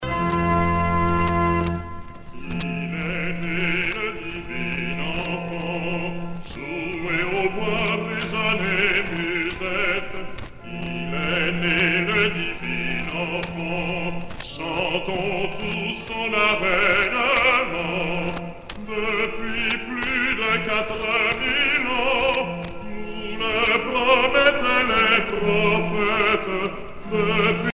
Noël
Pièce musicale éditée